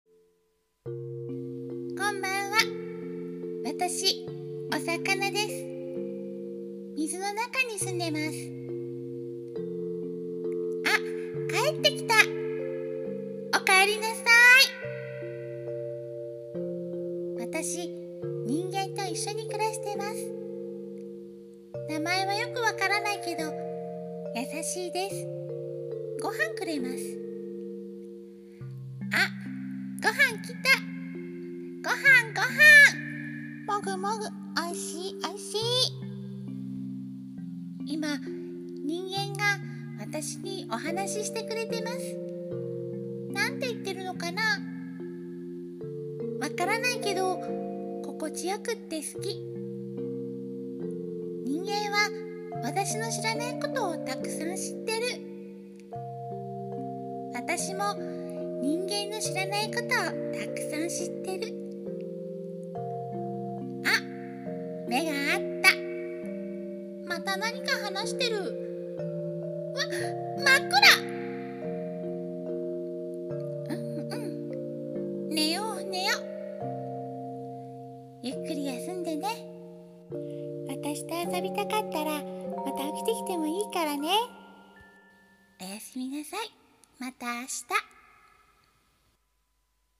【一人声劇】おかえりおかえり